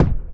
EXPLOSION_Subtle_Thump_Fade_stereo.wav